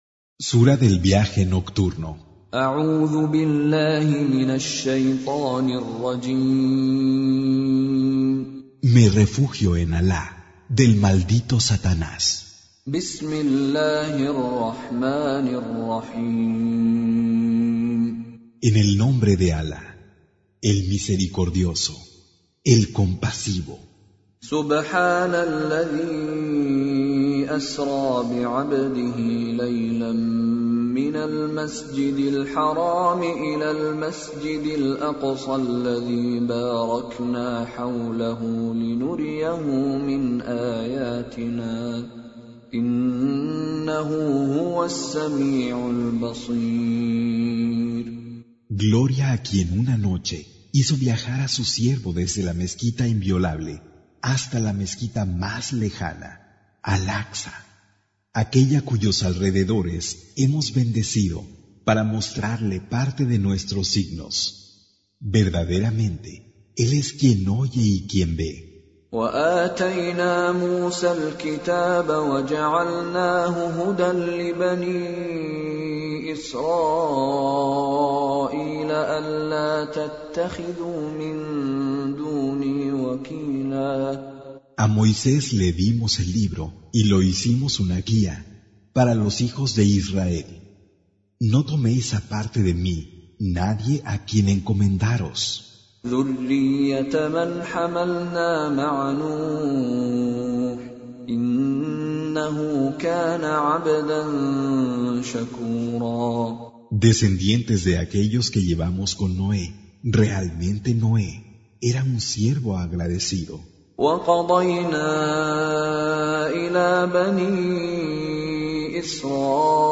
Recitation
Con Reciter Mishary Alafasi